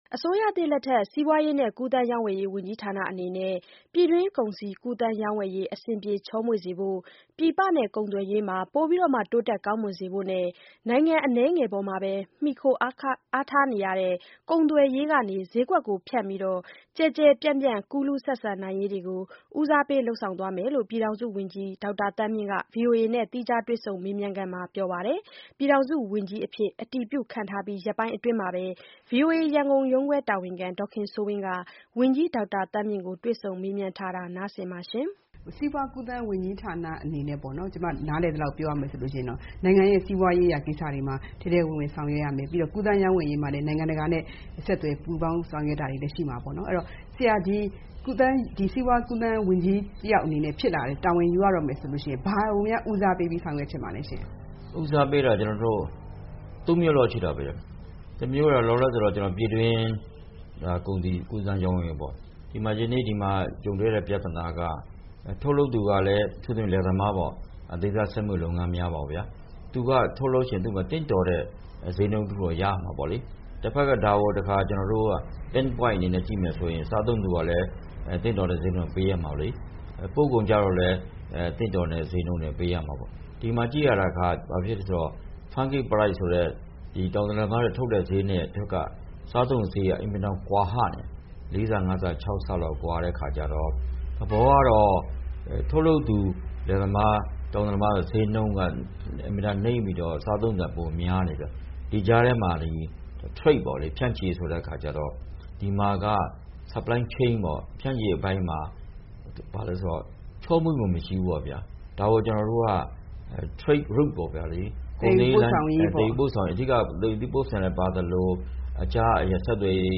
၀န်ကြီး ဒေါက်တာ သန်းမြင့်နဲ့ တွေ့ဆုံ မေးမြန်းချက်